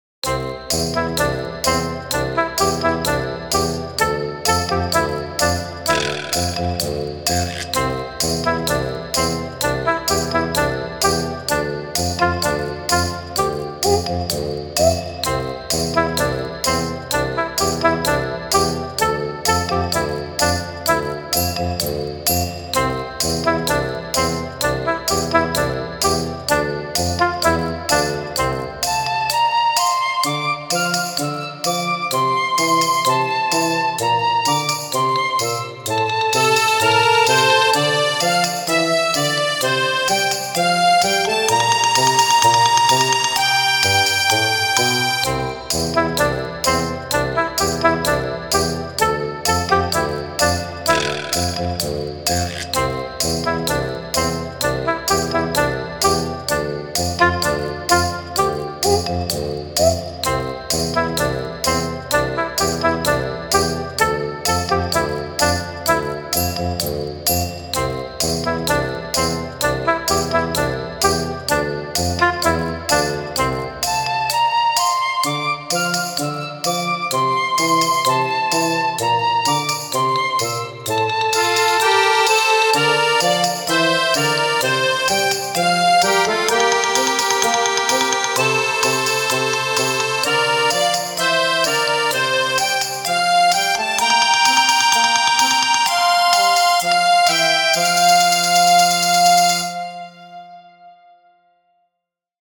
リズムには軽やかなパーカッションを使い、テンポを少し揺らすことで自然な“息づかい”を感じさせています。
主旋律はオーボエが担当し、スタッカートで小さな生き物の動きを表現。
中盤からはアコーディオンとフルートが加わり、広がりと温かさを演出します。
全体は長調ベースですが、ところどころにマイナーの香りを入れ、森の静けさや夕暮れ感も含ませています。